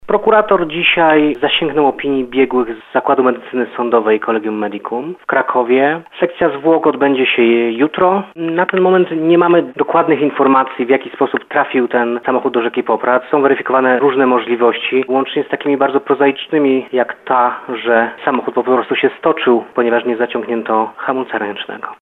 Śledczy wstępnie wykluczyli udział osób trzecich w tym zdarzeniu, ale możliwych scenariuszy jego przebiegu wciąż jest wiele – informuje prokurator rejonowy w Nowym Sączu Bartosz Gorzula.